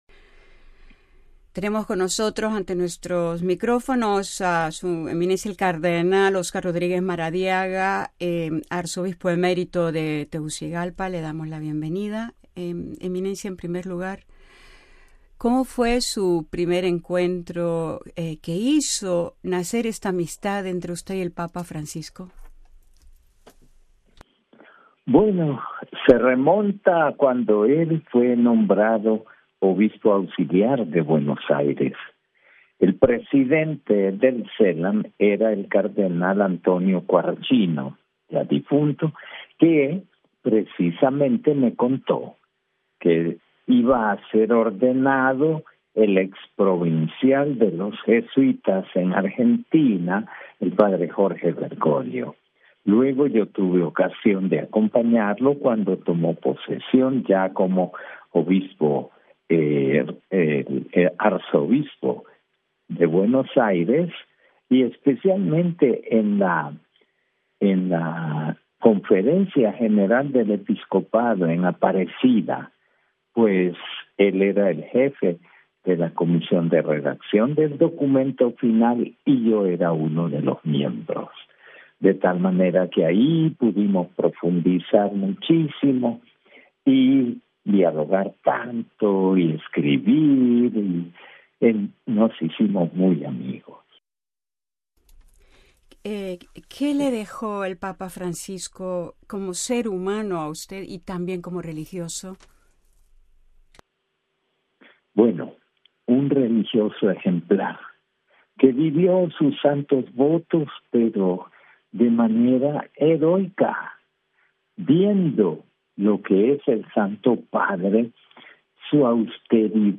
In an interview with the Vatican News, Cardinal Honduro is touring that long and wonderful friendship that he had with Pope Francis, because he was the bishop of Buenos Aires, working together in the document that appeared.